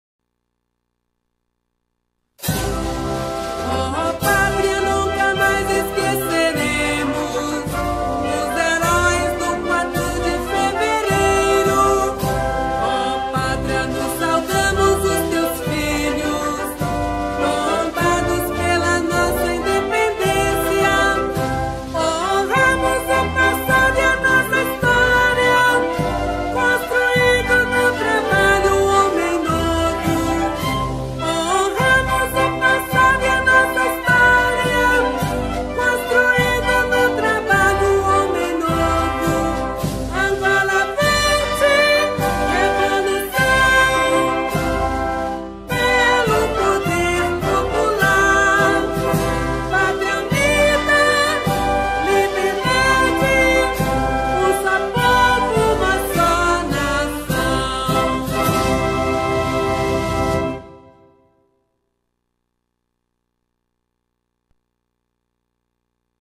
с вокальным исполнением